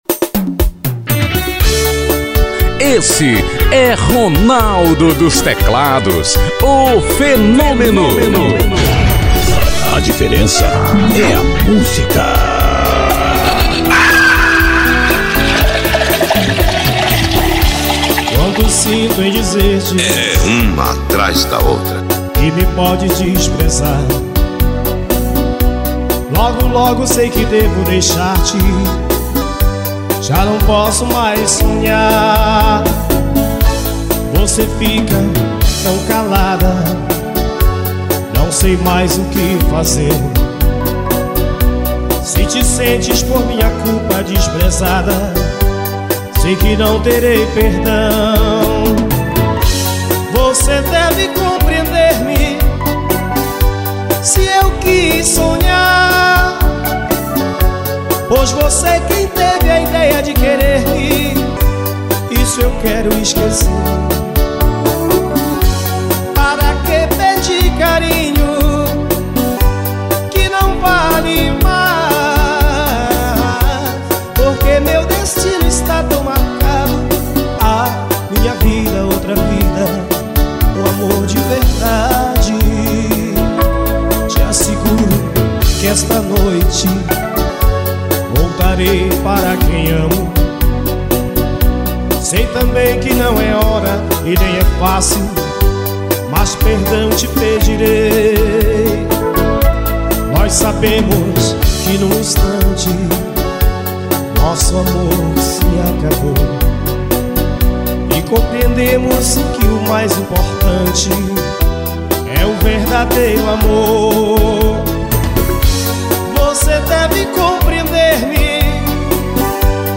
AO VIVO MACAPA-AP.